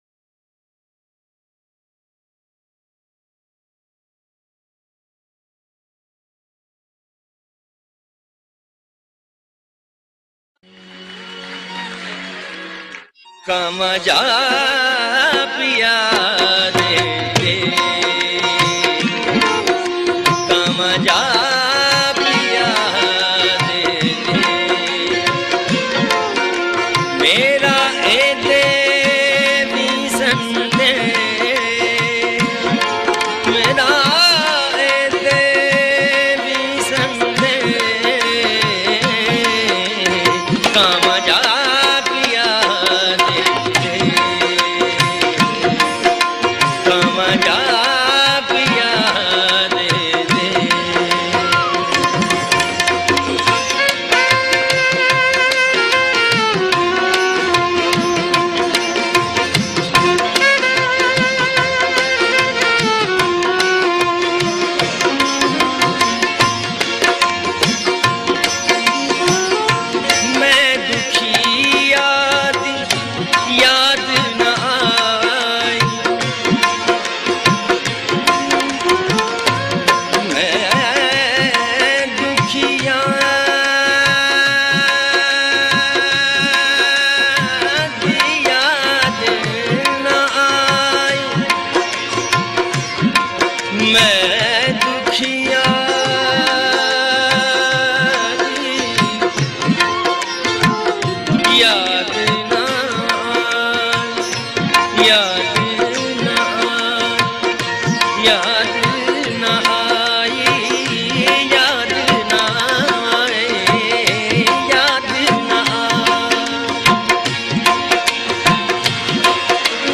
Ghazal, Punjabi Folk Songs